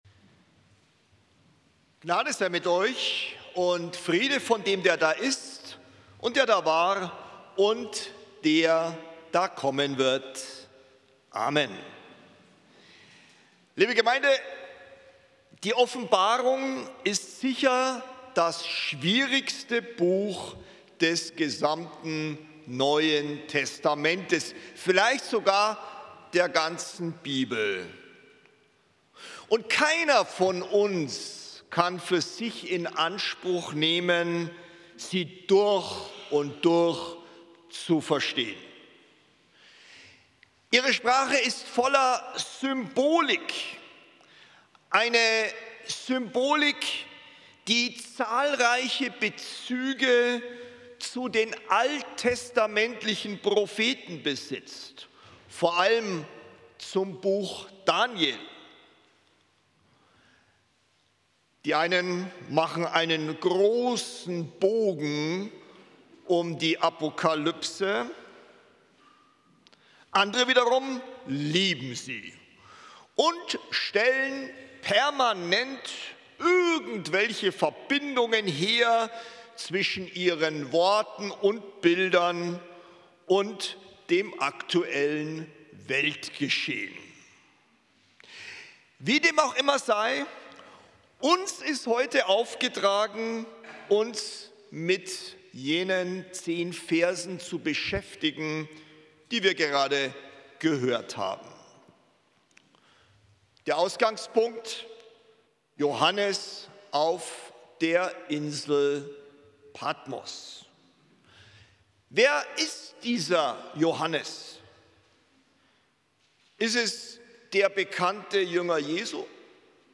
Predigt vom 12.04.2026 Spätgottesdienst